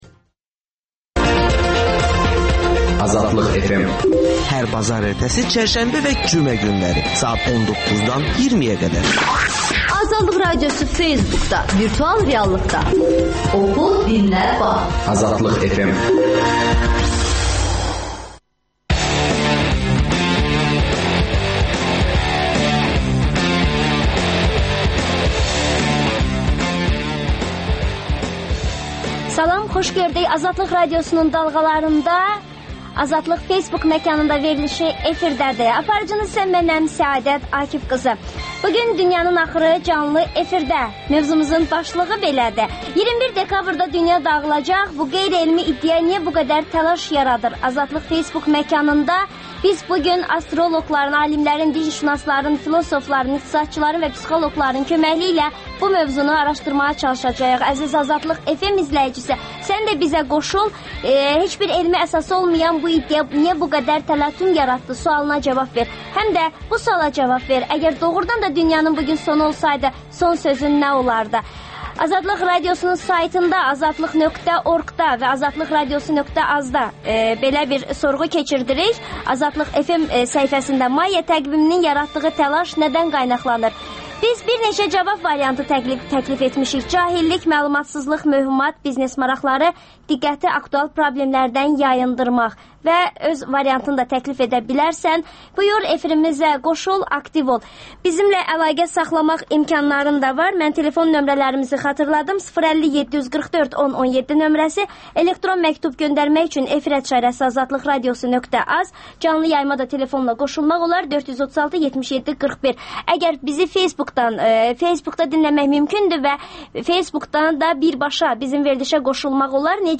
AzadliqFM - Dünyanın axırı canlı efirdə